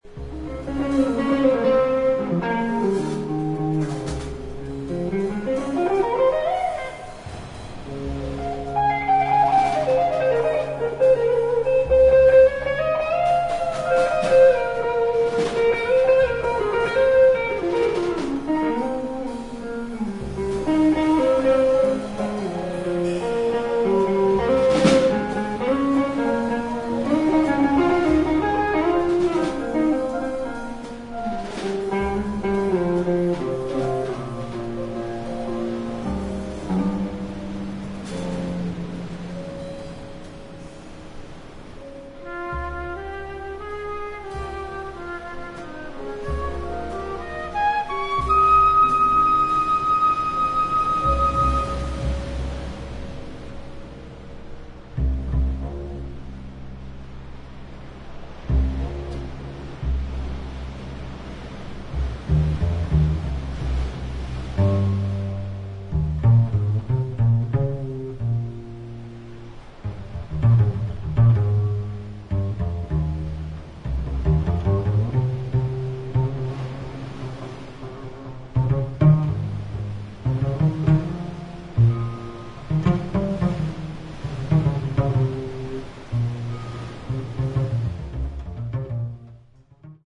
本作は、フェロー諸島にある断崖の渓谷の洞窟でレコーディングされたライヴ音源。